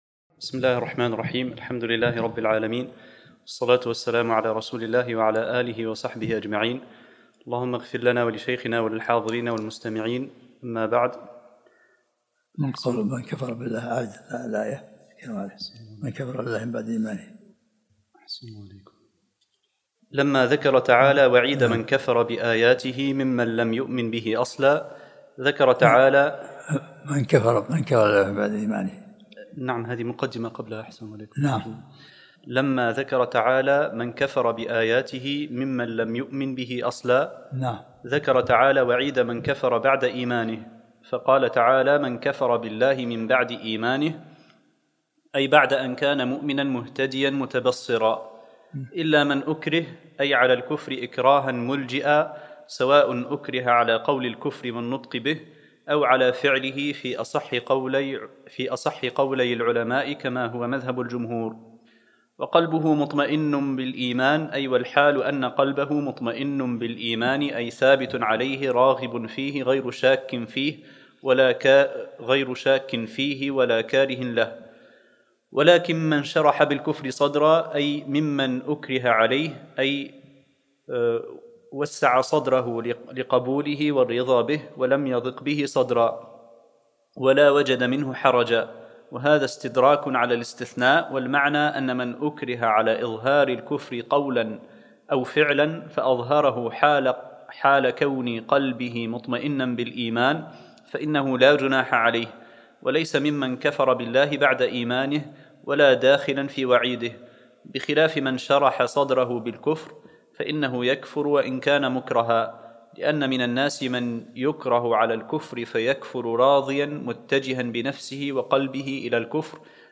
الدرس الثالث عشر من سورة النحل